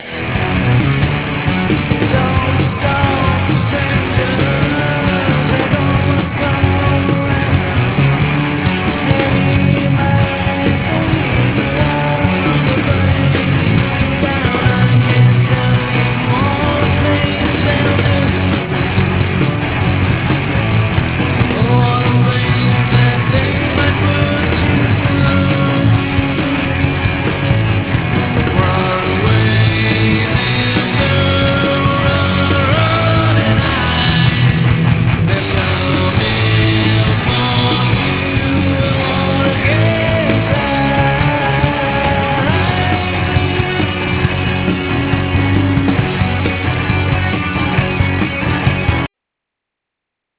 He even came up with most of the keyboard parts.